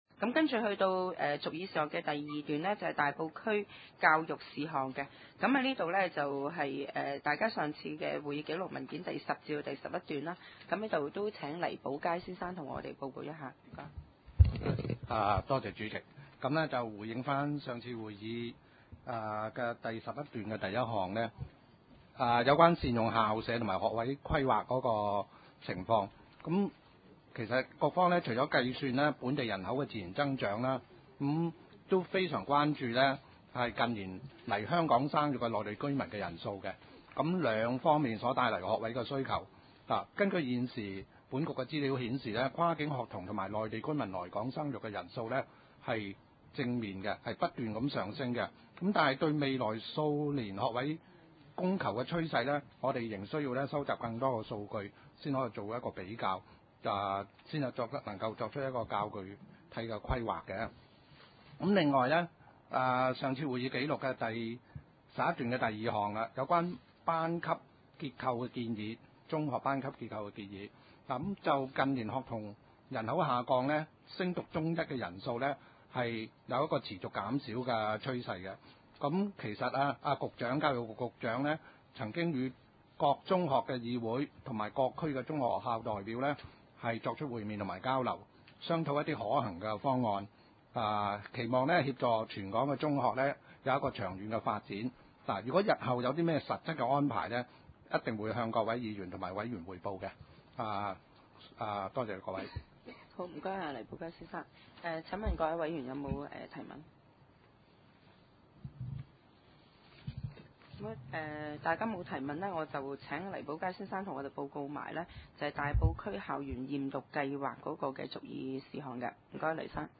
大埔區議會 大埔區議會社會服務委員會2010年第二次會議 日期：2010年3月10日 (星期三) 時間：上午9時30分 地點：大埔區議會秘書處會議室 議 程 討論時間 I. 通過社會服務委員會 2010 年第一次會議 (13.1.2010) 記錄 00:29 ( 大埔區議會文件 SS 11/2010 號 ) II.